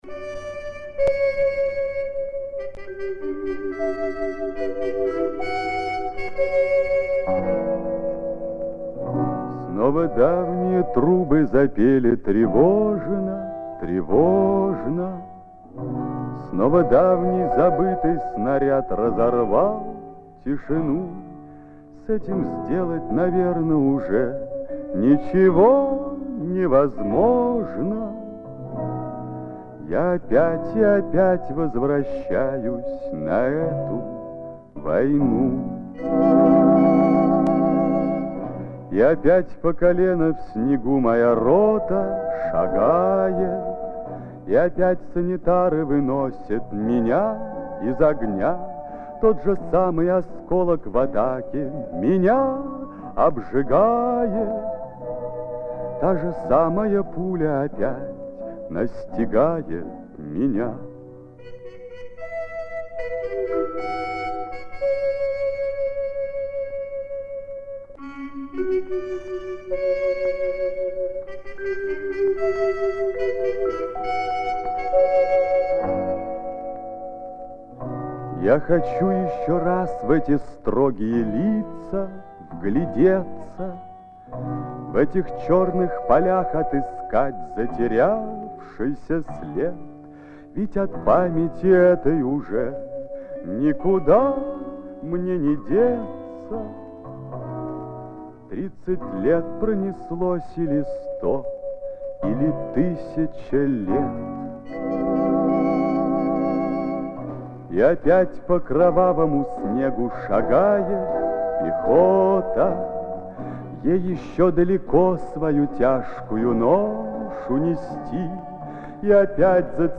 Очень напоминает Марка Бернеса.